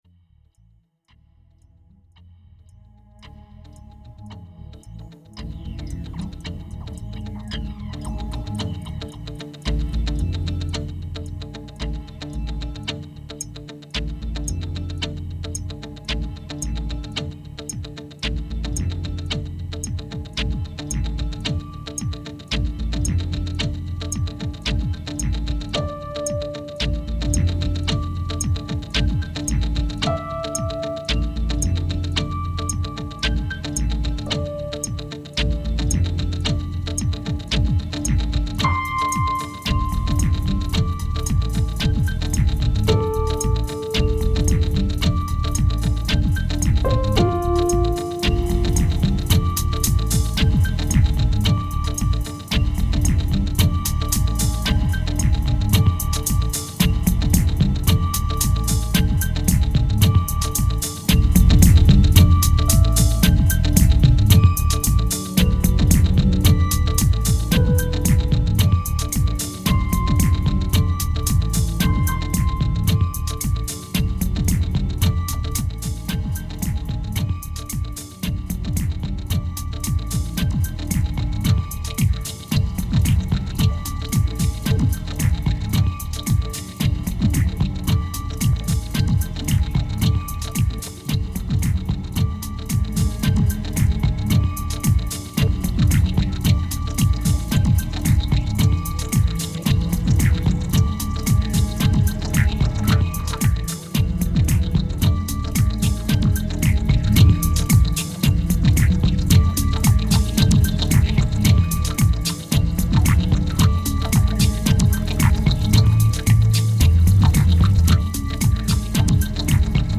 Ambient House Minimal Chord Piano Guitar Fake Lame